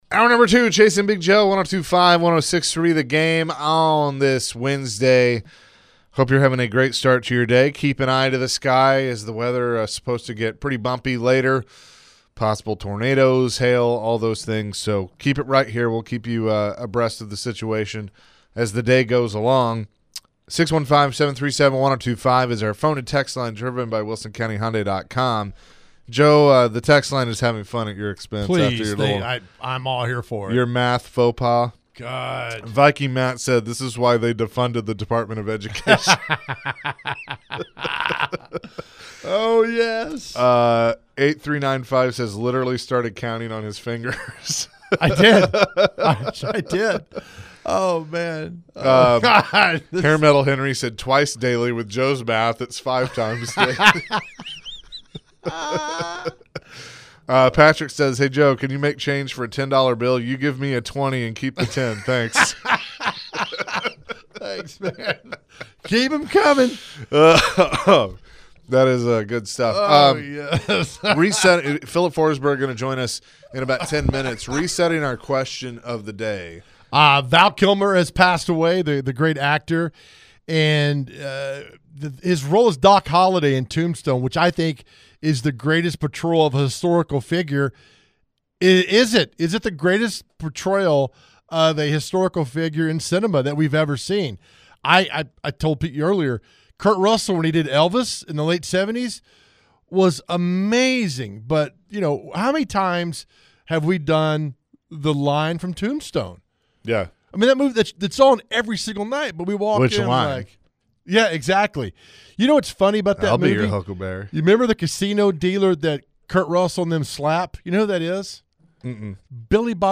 We get back into the question of the day, and which actor played the best role in a historical biopic after the passing of Val Kilmer? Preds F Filip Forsberg joins the show as they come down the final stretch of the season.
We get back into our question of the day, take your phones, and wrap up with What Day is it and Celeb Bdays.